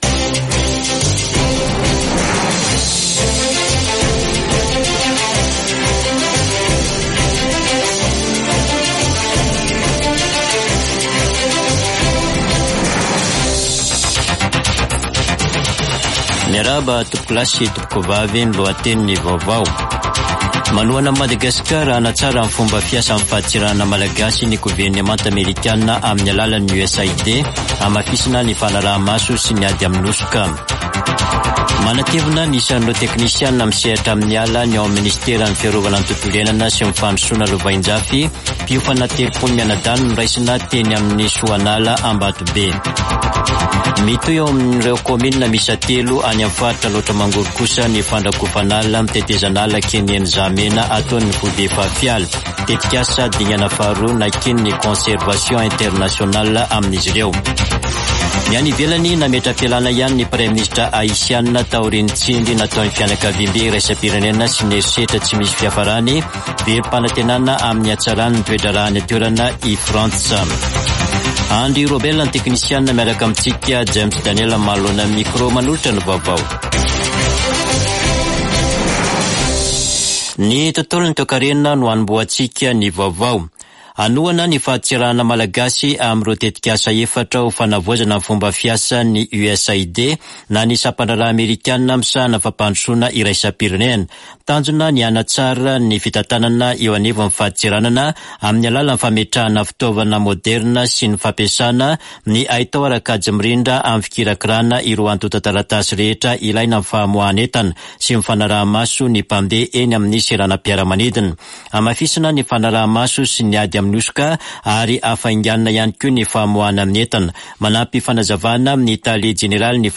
[Vaovao hariva] Talata 12 marsa 2024